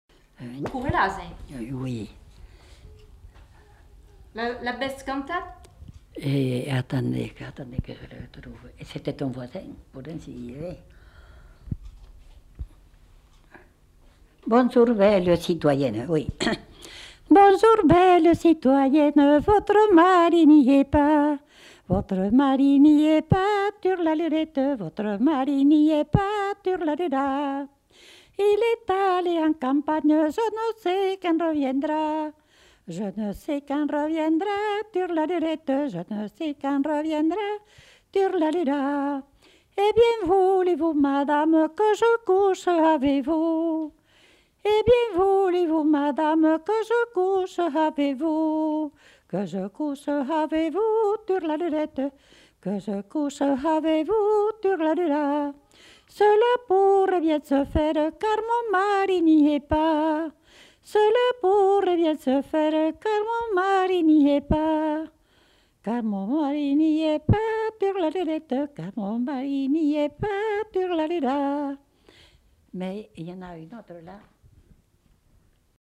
Aire culturelle : Haut-Agenais
Lieu : Condezaygues
Genre : chant
Effectif : 1
Type de voix : voix de femme
Production du son : chanté
Classification : chanson de charivari